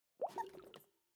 Minecraft Version Minecraft Version snapshot Latest Release | Latest Snapshot snapshot / assets / minecraft / sounds / mob / axolotl / idle4.ogg Compare With Compare With Latest Release | Latest Snapshot